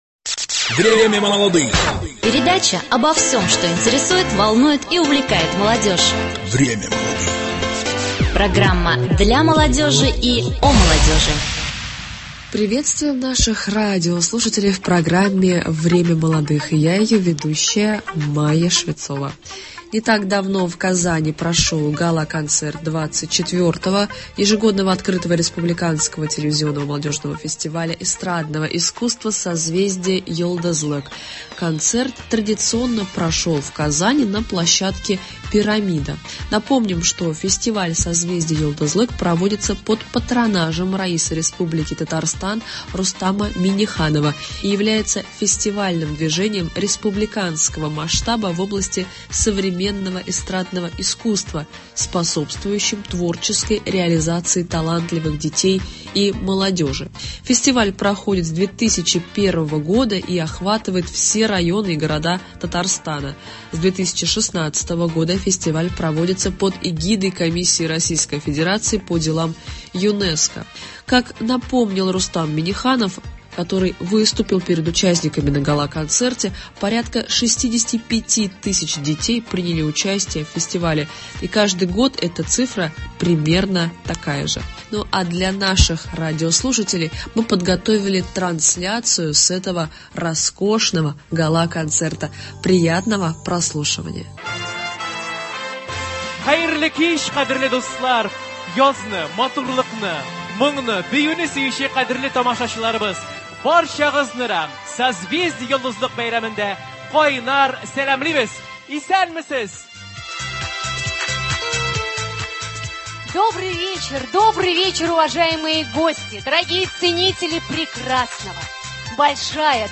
Трансляция с гала-концерта победителей XXIV фестиваля «Созвездие-Йолдызлык».